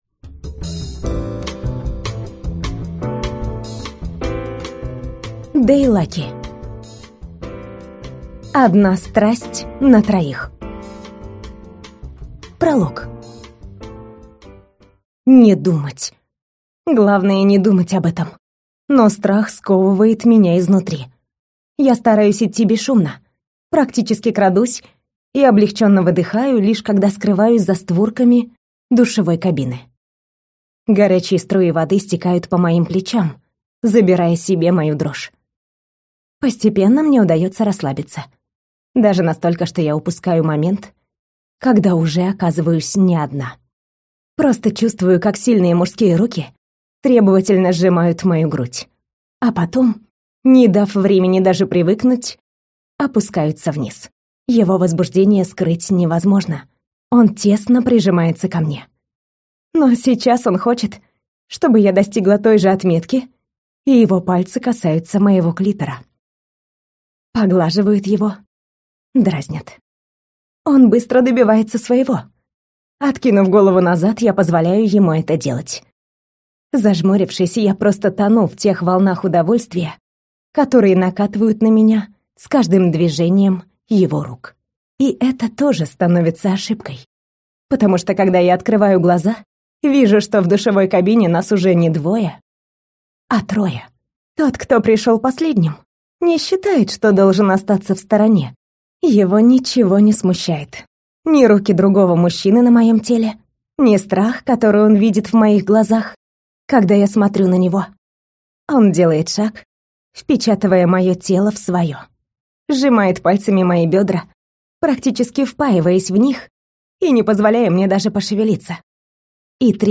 Аудиокнига Одна страсть на троих | Библиотека аудиокниг